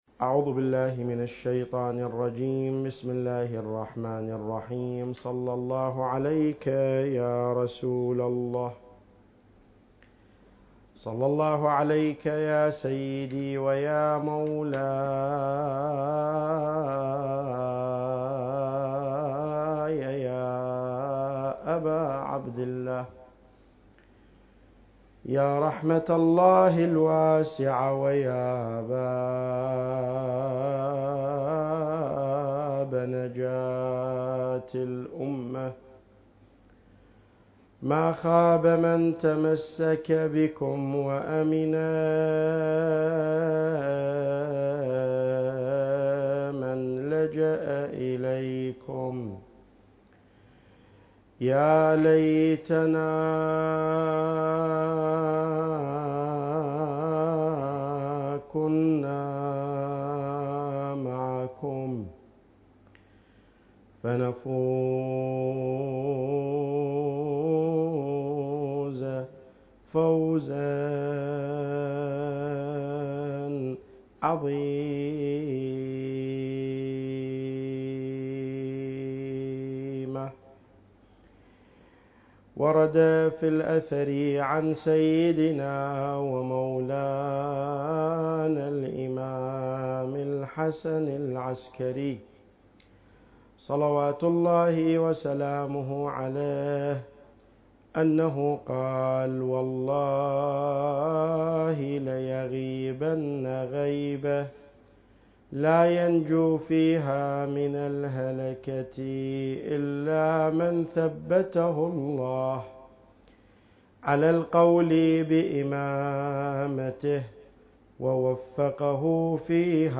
محاضرات عاشورائية المكان: معهد تراث الأنبياء - النجف الأشرف التاريخ: محرم الحرام 1444 للهجرة